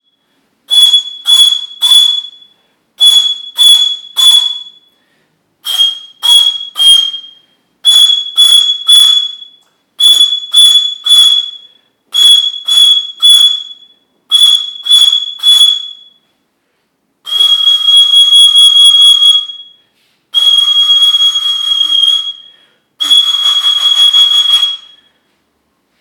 Los piloilos de dos tubos parecen representar una familia distinta a los de más tubos, ya que sus posibilidades de tañido ofrecen un sonido más percutido en el que cada tubo se sopla de manera individual. Esta forma de ejecución se basa en las actuales pifilka utilizadas en los complejos rituales/sonoros mapuche. Este piloilo, solo puede ejecutarse al tapar la abertura inferior de sus tubos.
Piloilo de dos tubos abiertos.